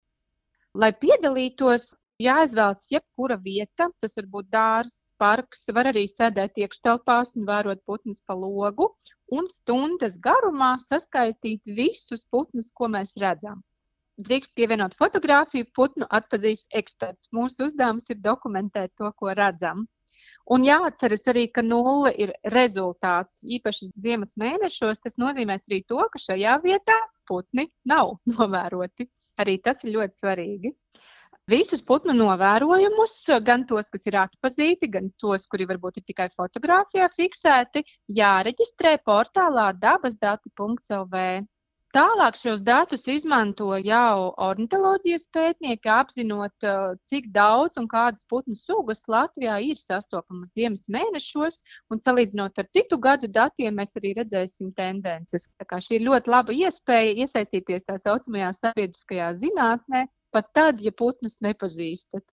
RADIO SKONTO Ziņās par aicinājumu piedalīties putnu skaitīšanā